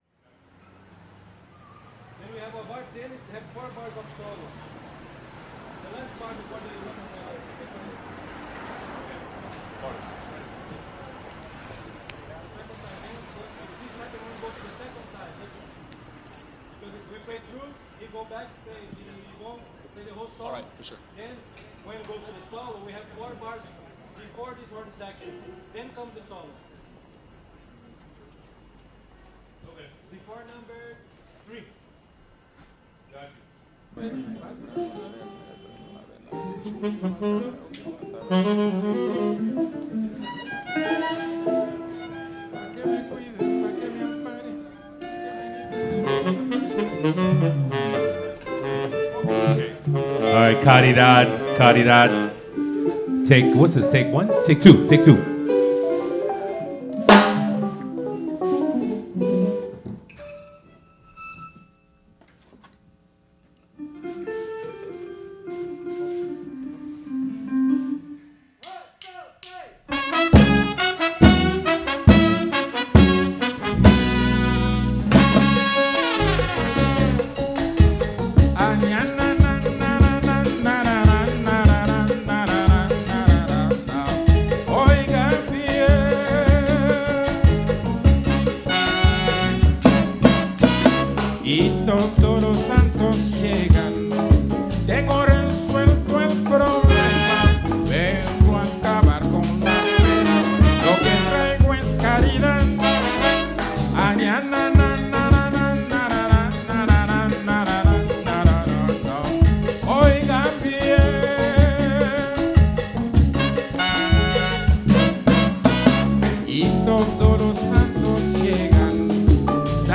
African International Jazz Latin Live Performance